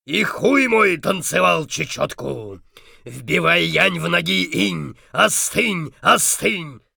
scav3_mutter_34_bl.wav